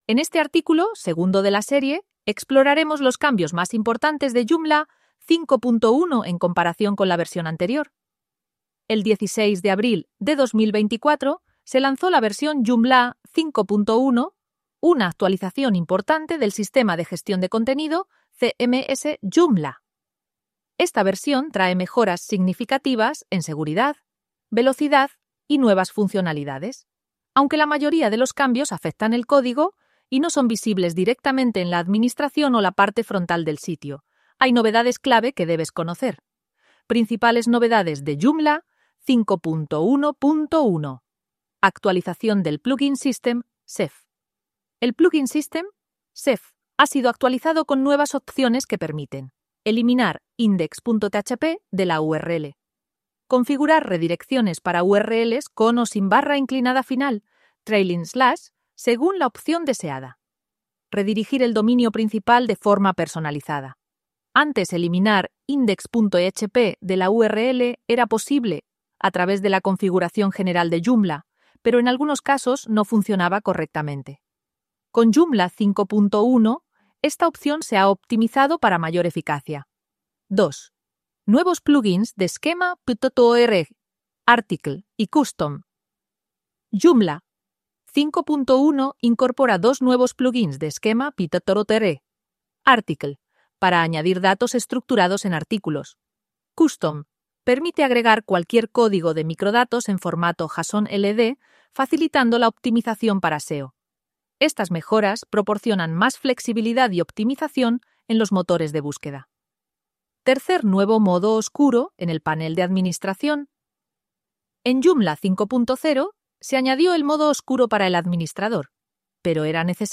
Podcast sobre Joomla en español con JoomlIA Robers, una IA